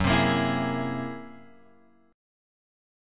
F#9b5 chord {2 3 x 3 5 4} chord